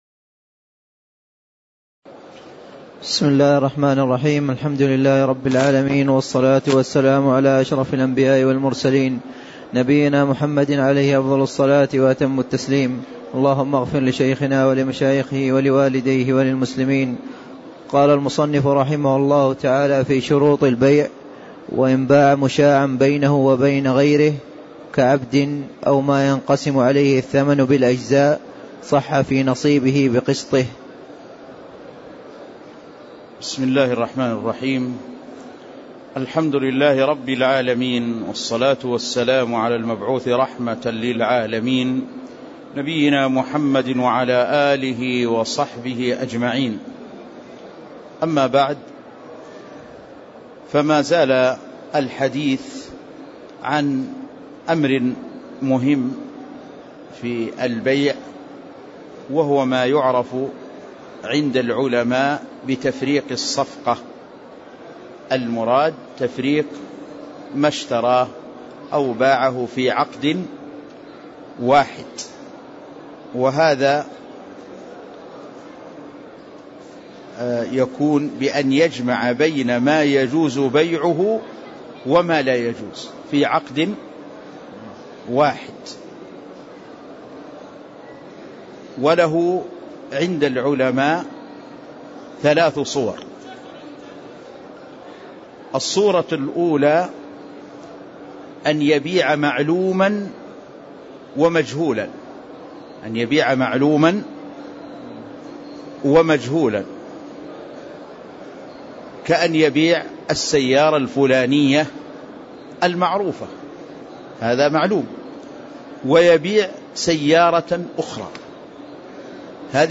تاريخ النشر ١٨ جمادى الآخرة ١٤٣٦ هـ المكان: المسجد النبوي الشيخ